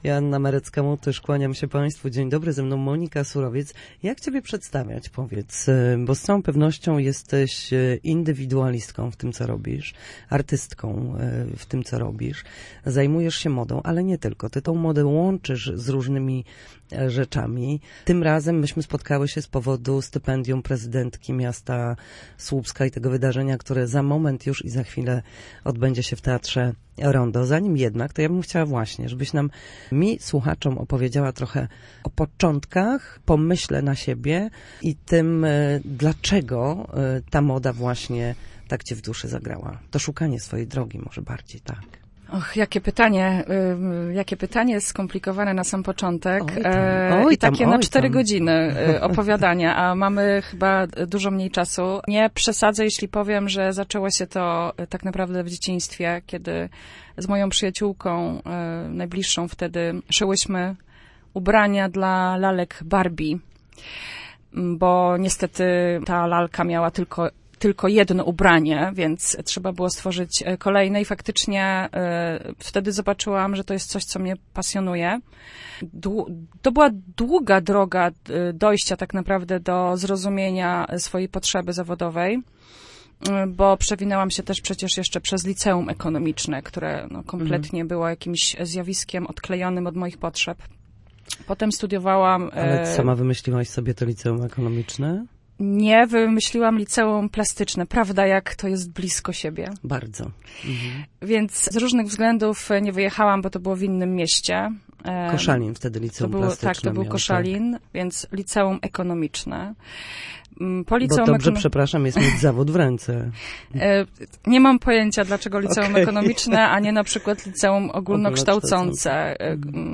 Na naszej antenie mówiła o początkach swojej zawodowej drogi, przede wszystkim jednak o projekcie „Odzyskane”, który realizowany będzie w najbliższy wtorek w Teatrze Rondo w Słupsku.